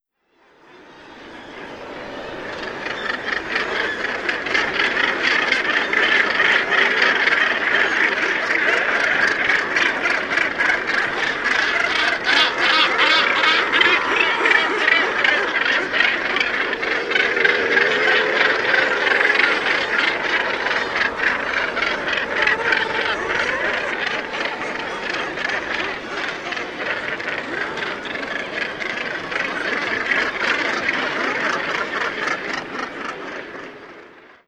• gannet colony sound.wav
gannet_colony_sound_LHS.wav